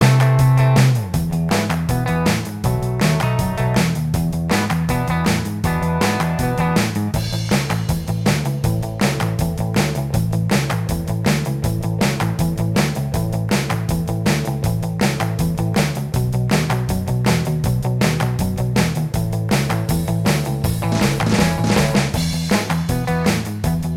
Minus Guitars Pop (1960s) 2:54 Buy £1.50